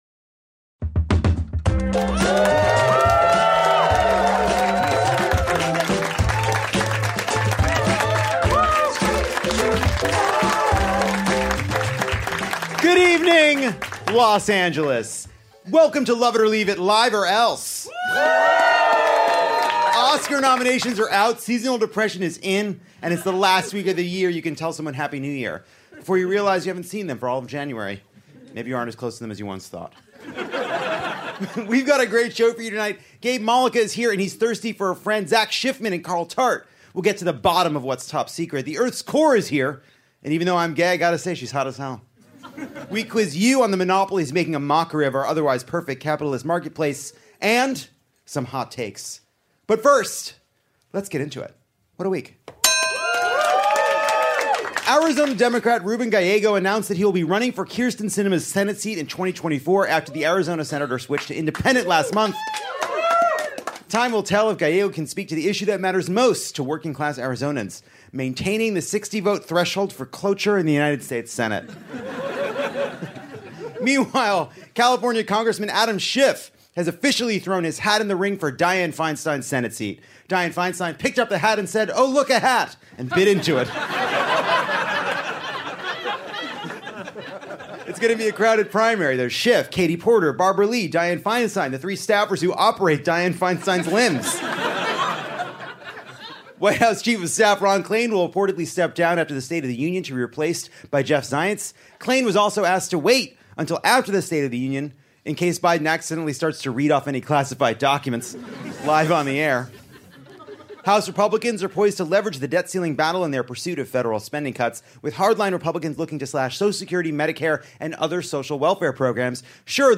Whether you’re on the lanai or inside Los Angeles’s beautiful Dynasty Typewriter theater, this week’s Lovett Or Leave It thanks you for being a friend.
Lovett has a monopoly on our live audience’s understanding of what the hell a monopoly is.